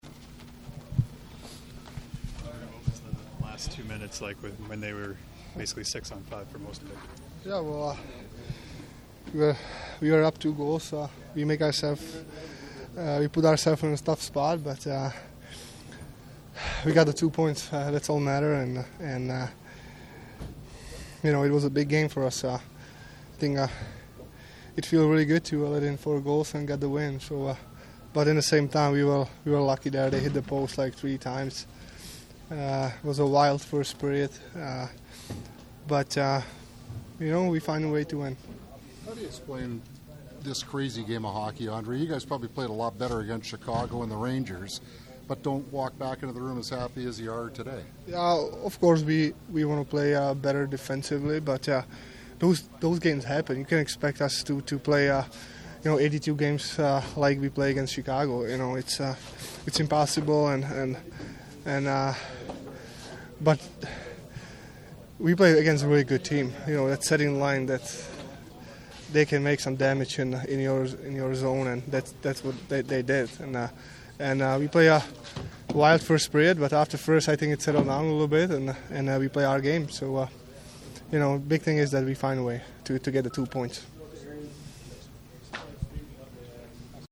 Post-game audio from the Jets dressing room.
Apr4-Pavelec-post-scrum.mp3